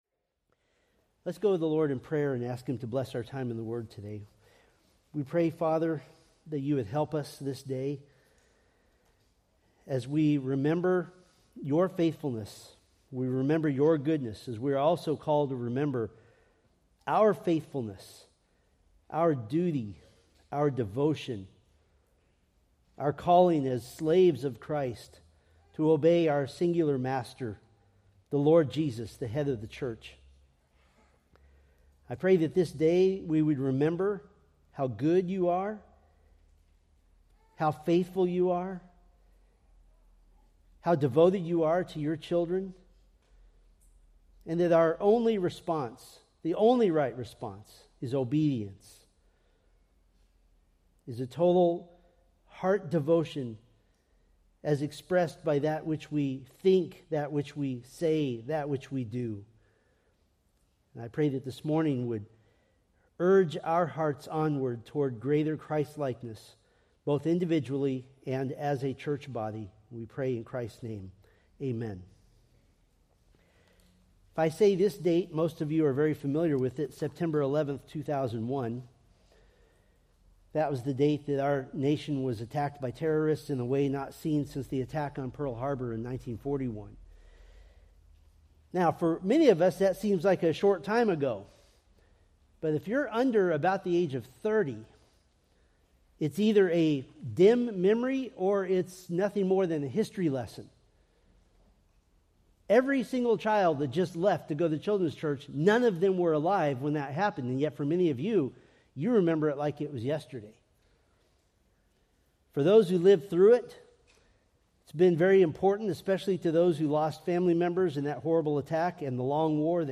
Preached March 16, 2025 from Selected Scriptures